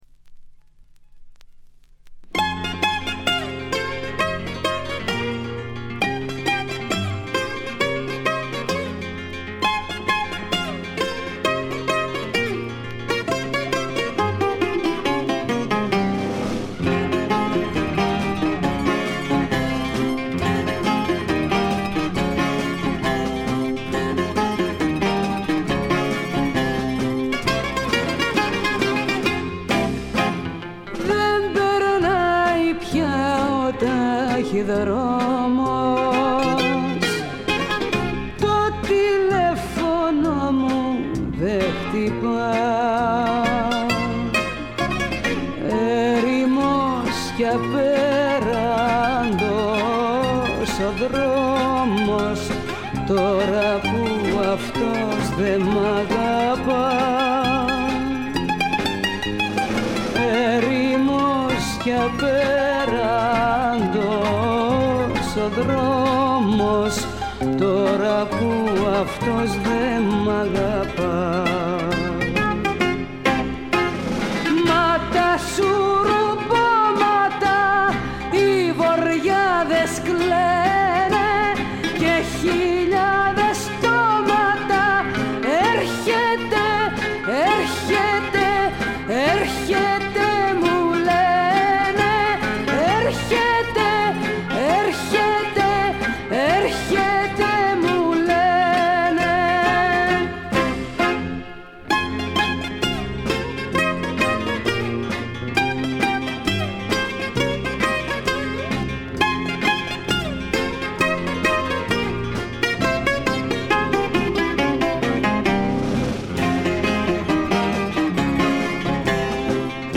わずかなノイズ感のみ。
しかしデビュー時にしてすでに堂々たる歌唱を聴かせてくれます。
試聴曲は現品からの取り込み音源です。